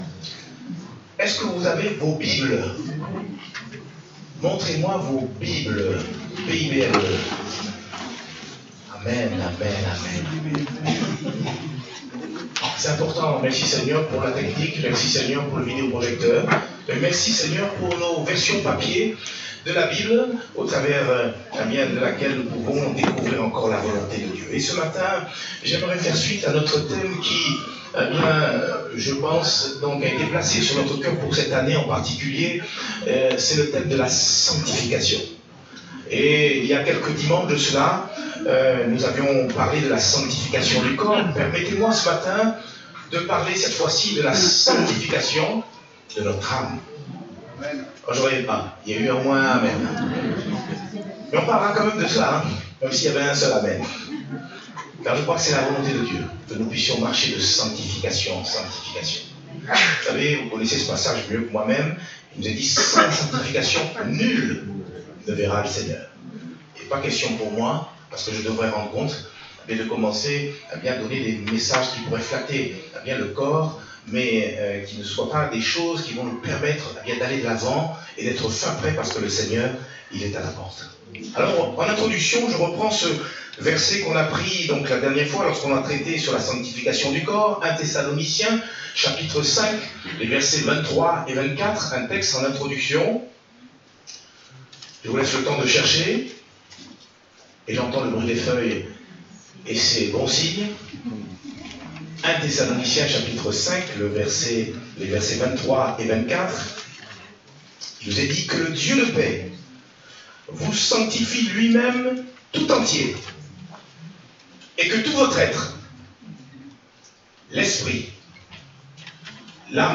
Date : 14 avril 2019 (Culte Dominical)